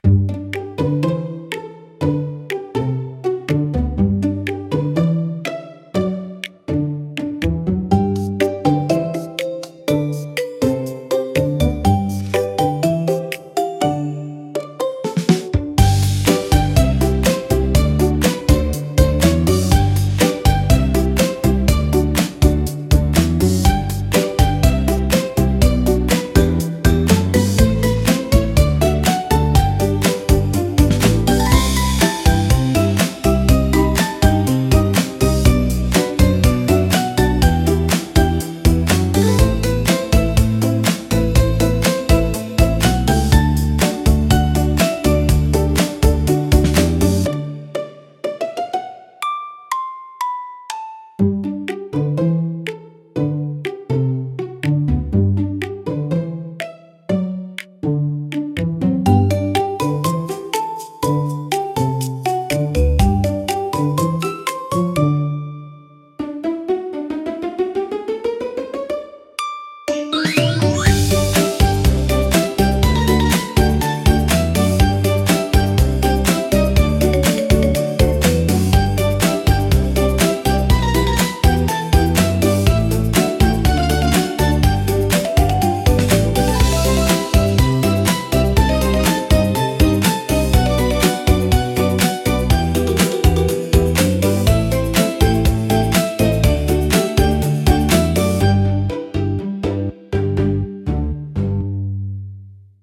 聴く人に安心感と温かさを届け、自然で可愛らしい空間を演出します。